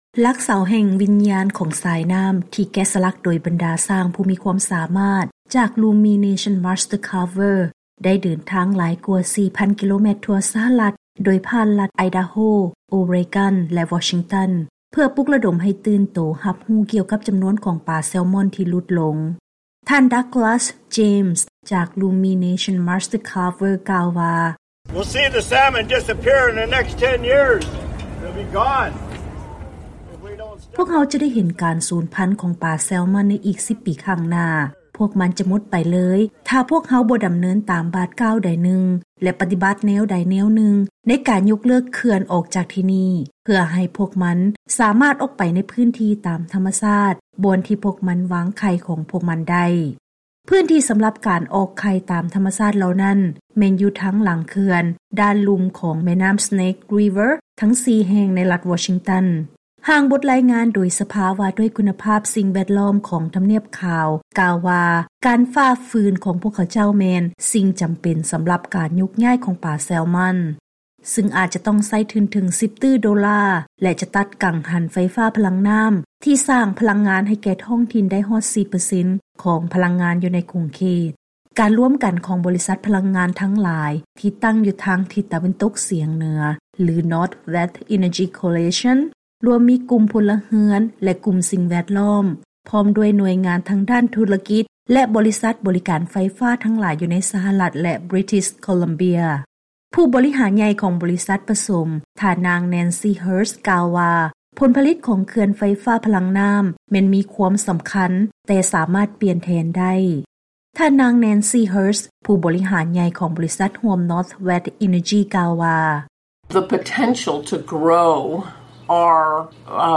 ເຊີນຟັງລາຍງານກ່ຽວກັບ ຊົນເຜົ່າພື້ນເມືອງຜັກດັນໃຫ້ທໍາລາຍເຂື່ອນ ເພື່ອການຂະຫຍາຍພັນຂອງປາເຊລມອນ